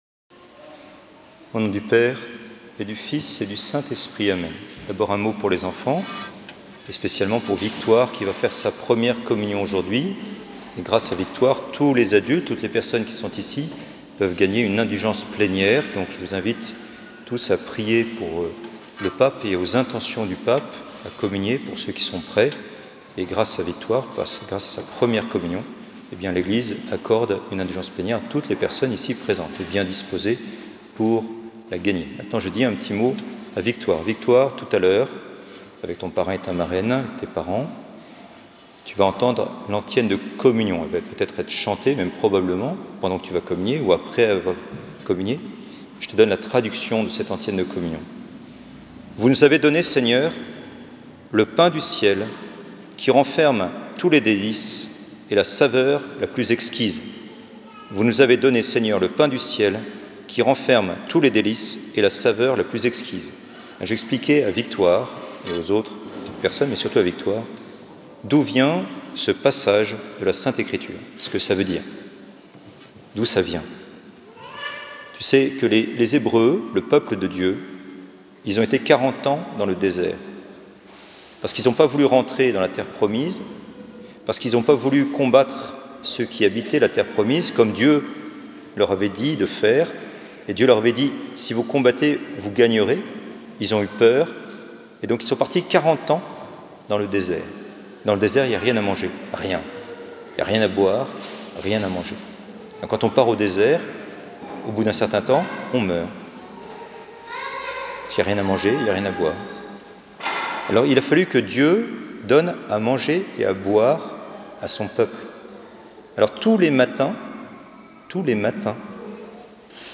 Homélies du dimanche